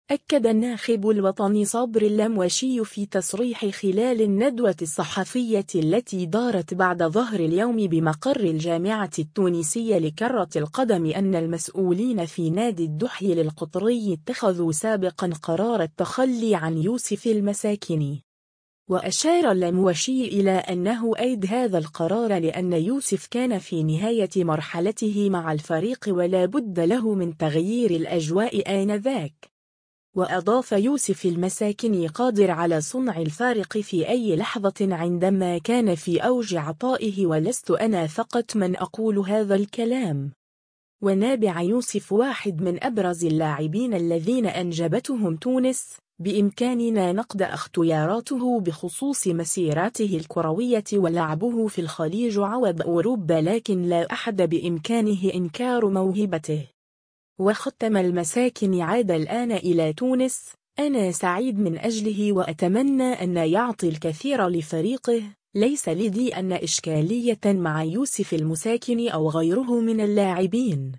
أكّد الناخب الوطني صبري اللموشي في تصريح خلال الندوة الصحفية التي دارت بعد ظهر اليوم بمقر الجامعة التونسية لكرة القدم أنّ المسؤولين في نادي الدحيل القطري اتخذوا سابقا قرار التخلي عن يوسف المساكني.